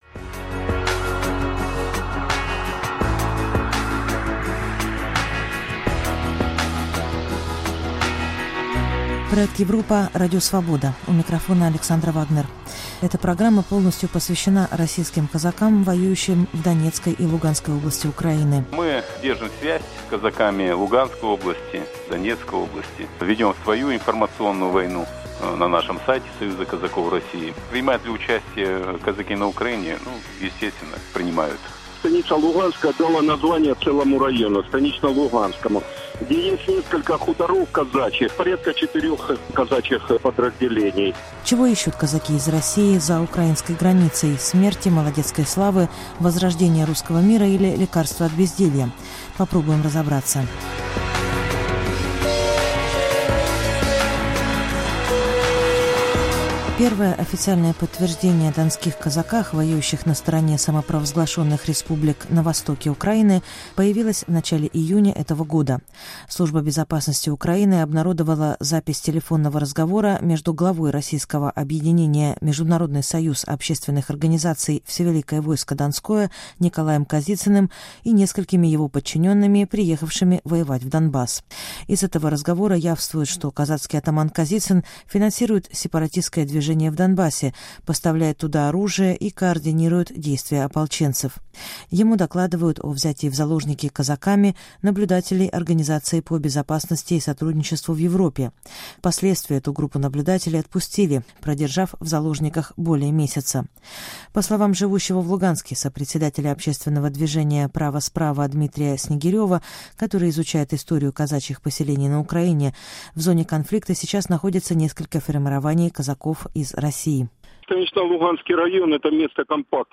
Чего ищут казаки из России за украинской границей – смерти, молодецкой славы, возрождения Русского мира или лекарства от безделья? Ответ на этот вопрос ищут два собеседника с разными мнениями - из России и Украины.